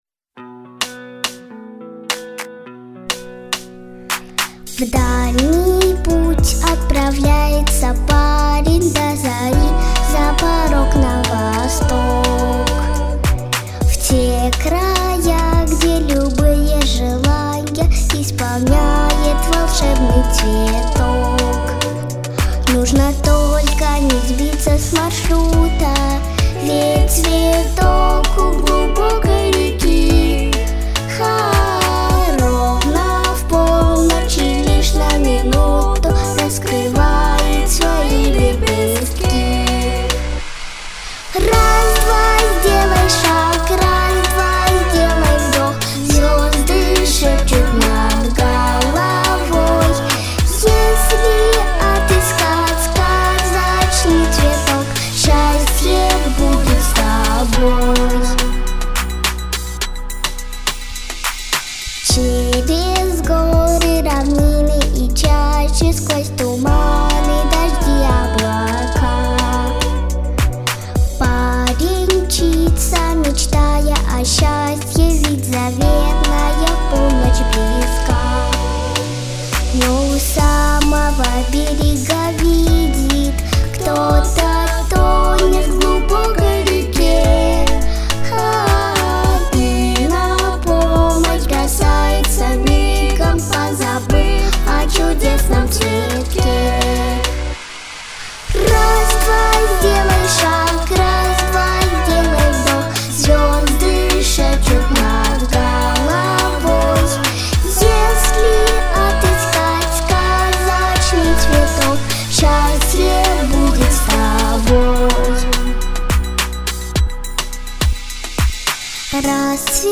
Характер песни: позитивный, лирический.
Темп песни: средний.
Диапазон: Ля малой октавы - Си первой октавы.